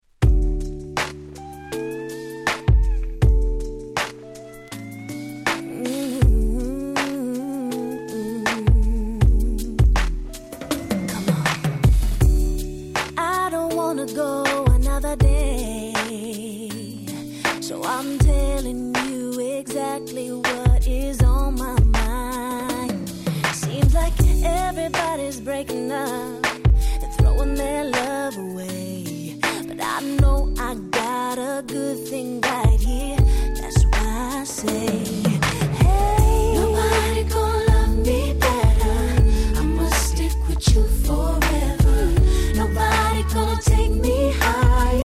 05' Super Hit Mellow Smooth R&B♪
特に2サビ後から終盤にかけてのブリッジ部分の込み上げ様って言ったら悶絶級ですよ、全く。